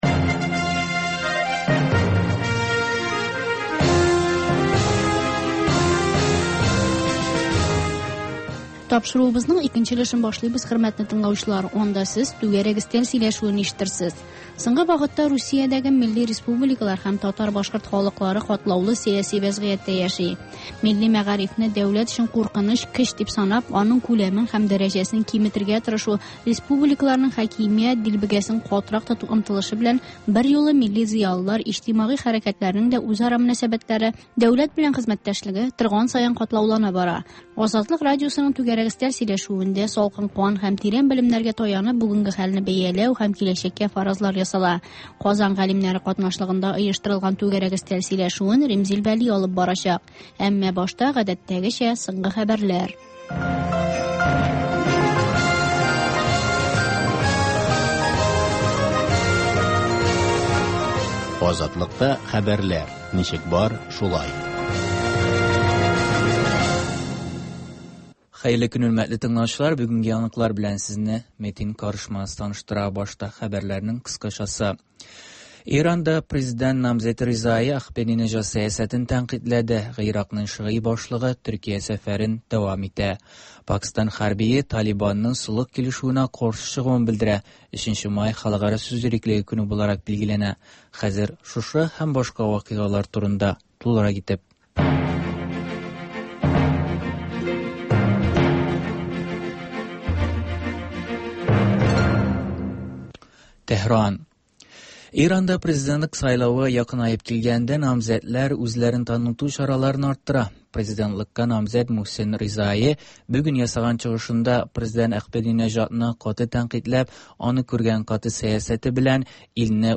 Азатлык узган атнага күз сала - соңгы хәбәрләр - түгәрәк өстәл сөйләшүе